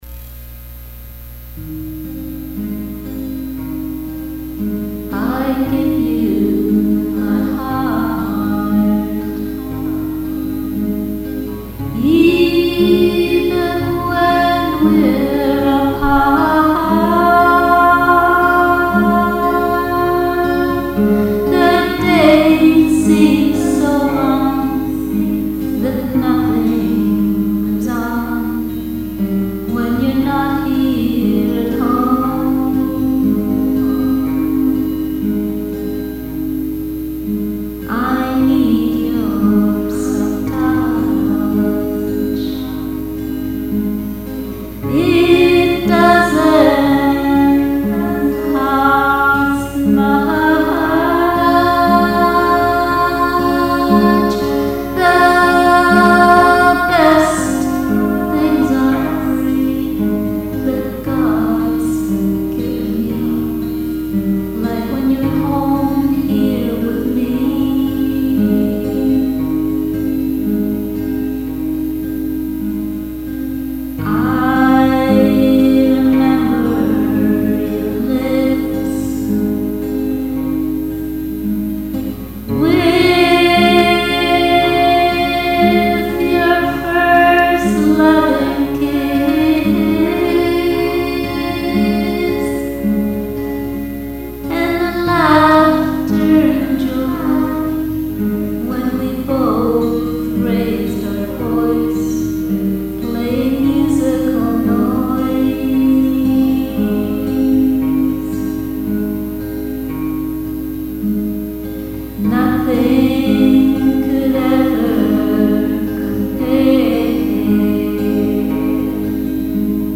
Ambient
Pop